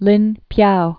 (lĭn pyou, byou)